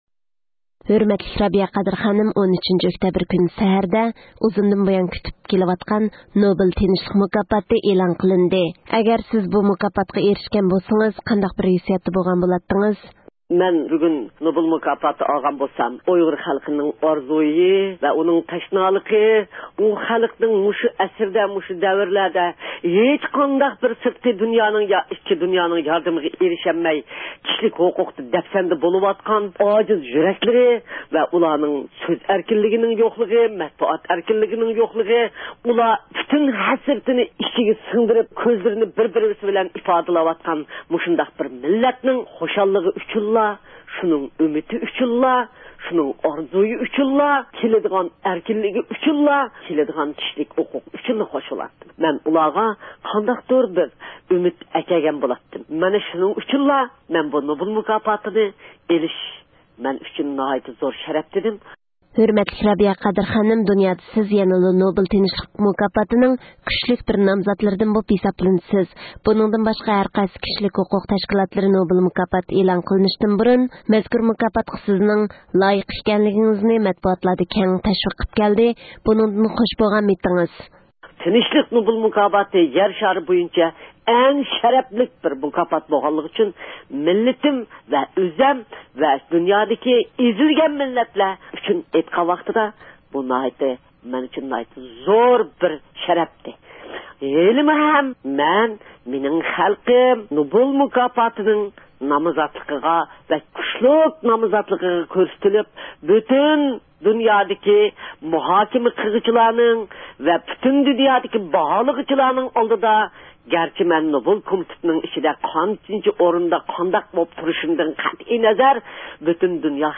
نوبېل تىنچلىق مۇكاپاتى ئېلان قىلىنغانلىقى مۇناسىۋىتى بىلەن رابىيە قادىر خانىمنى زىيارەت – ئۇيغۇر مىللى ھەركىتى